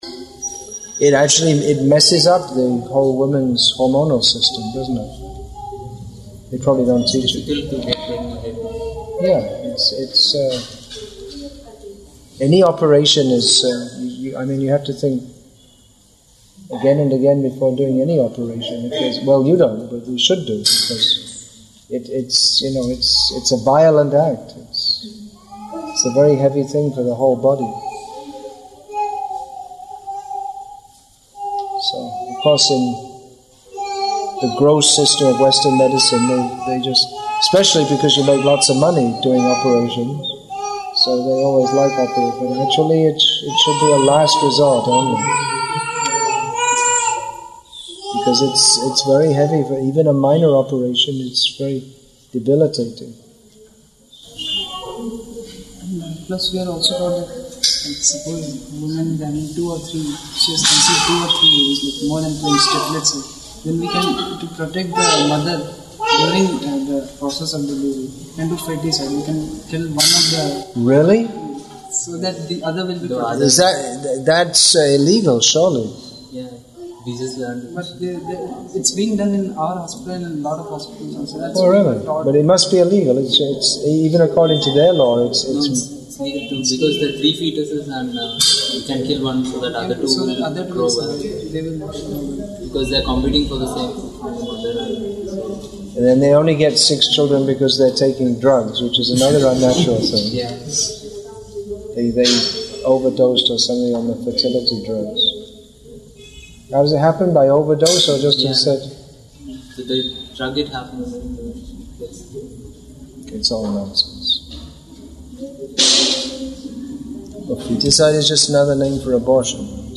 Conversation after Continuing Madhvacaryas’s legacy